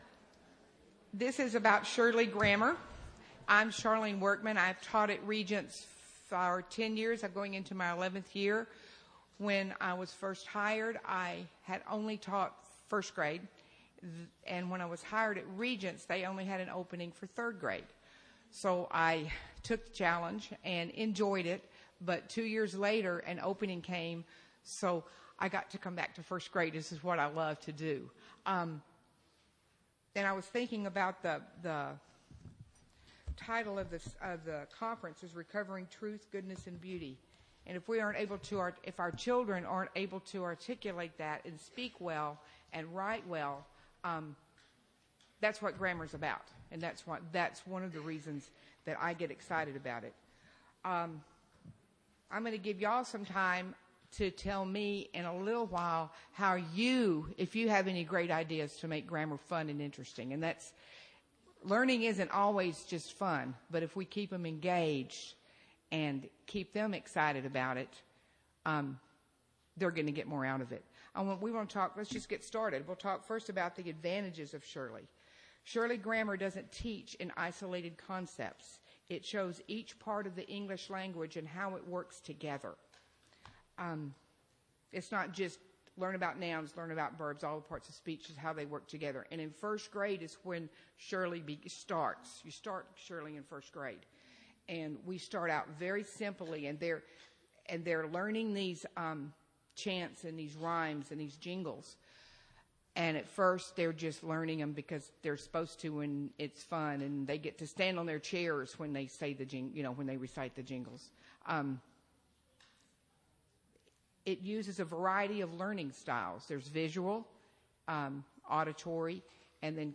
2008 Workshop Talk | 0:57:45 | K-6, Rhetoric & Composition
Jan 31, 2019 | Conference Talks, K-6, Library, Media_Audio, Rhetoric & Composition, Workshop Talk | 0 comments